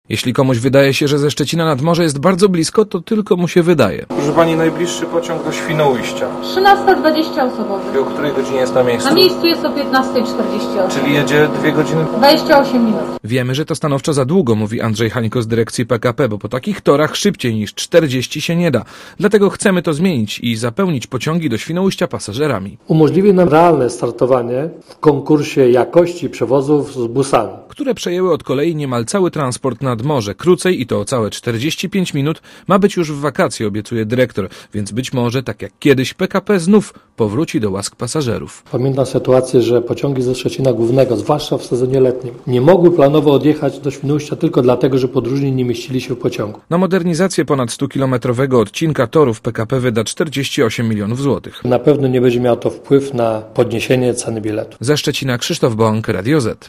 Relacja